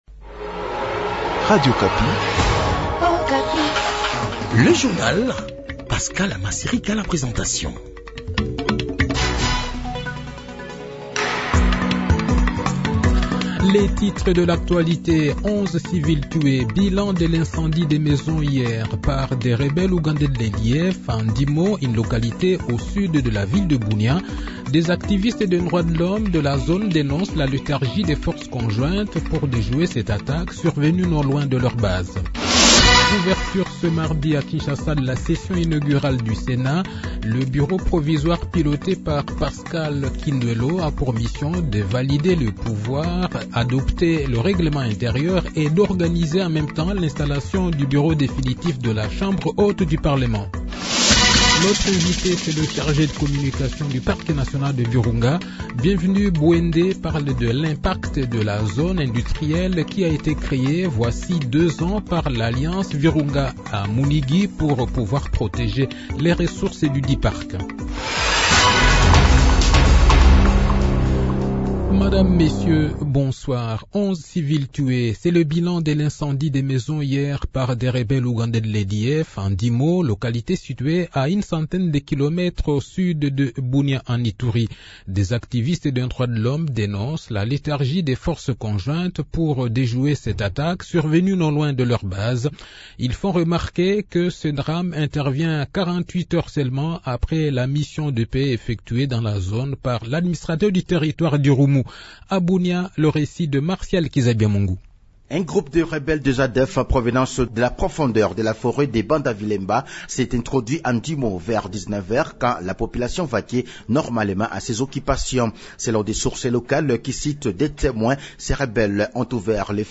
Le journal de 18 h, 14 mai 2024